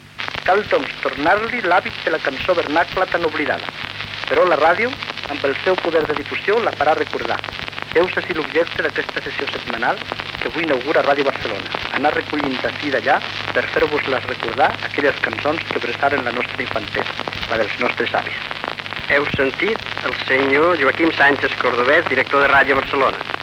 Musical